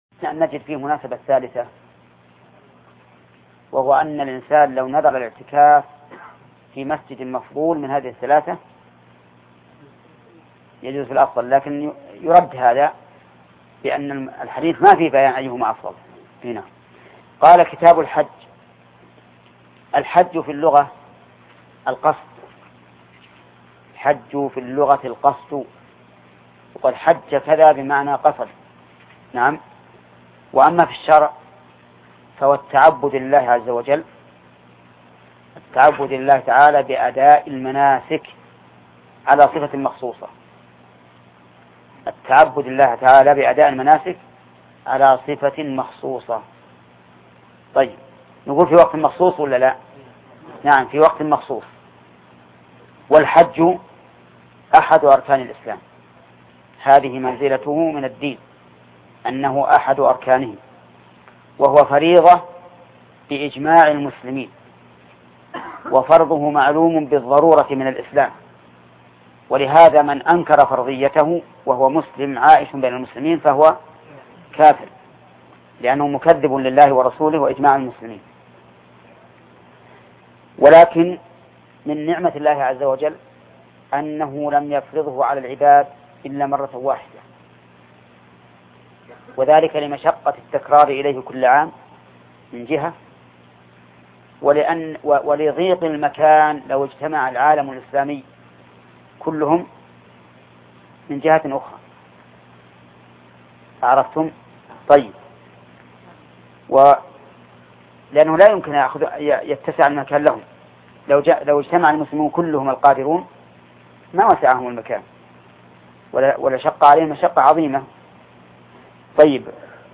بلوغ المرام من أدلة الأحكام شرح الشيخ محمد بن صالح العثيمين الدرس 112